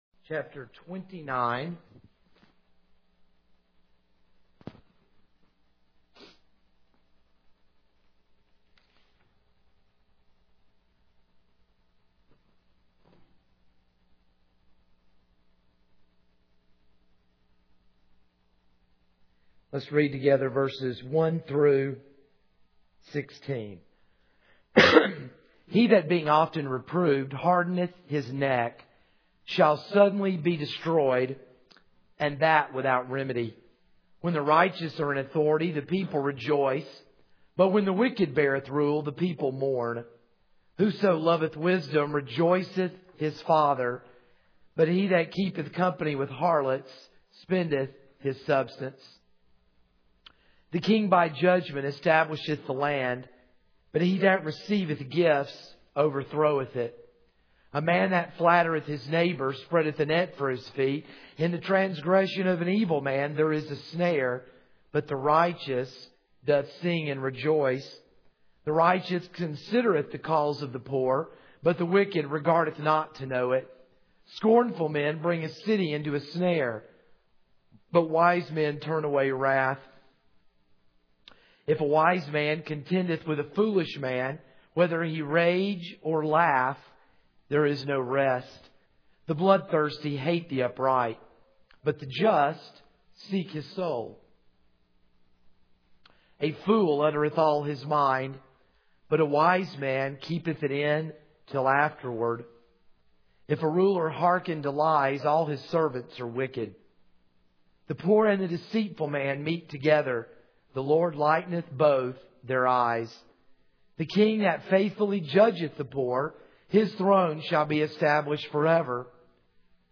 This is a sermon on Proverbs 29:1-16 (Part 1 of 2).